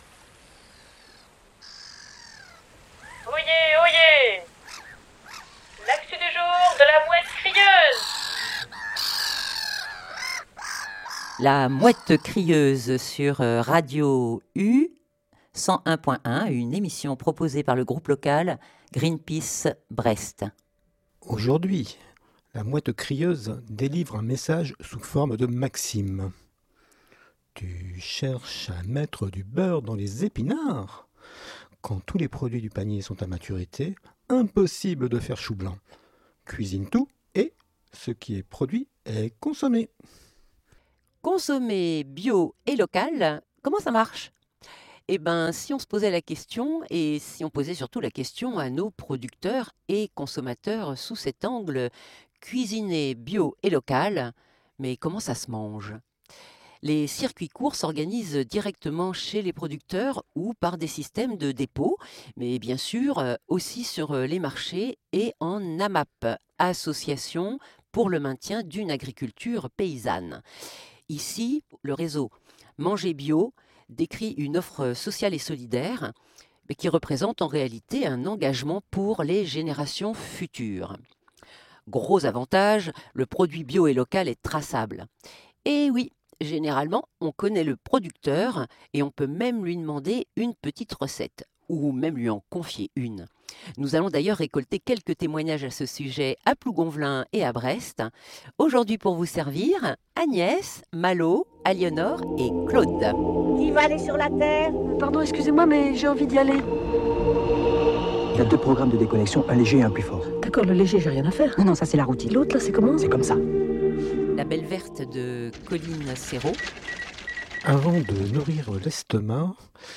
La mouette crieuse mène son enquête sur les marchés et en AMAP, Association pour le Maintien d’une Agriculture Paysanne. Producteurs et consommateurs répondent : cuisinez bio et local , comment ça se mange !